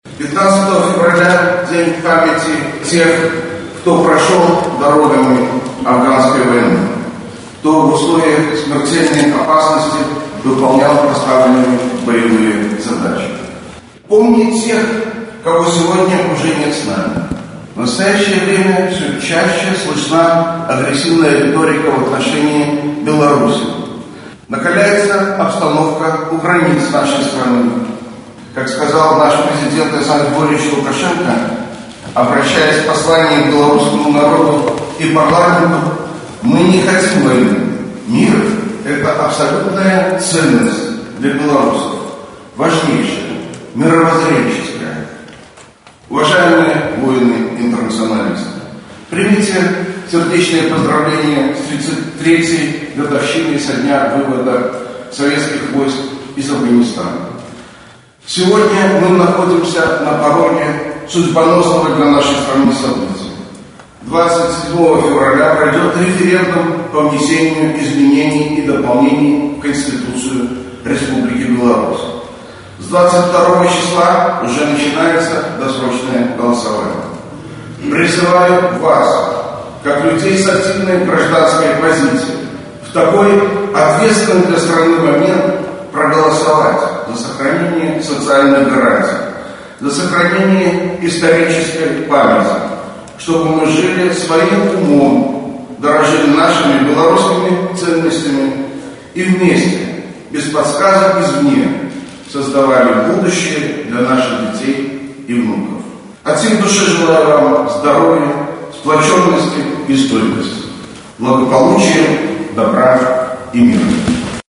В большом зале городского исполнительного комитета собрались воины-интернационалисты, члены их семей, депутаты, представители общественных организаций, руководители предприятий. К присутствующим обратился председатель горисполкома Юрий Громаковский. Он поблагодарил афганцев за то, что они свято чтят память земляков, не вернувшихся домой, принимают активное участие в общественной жизни и трудятся на благо города.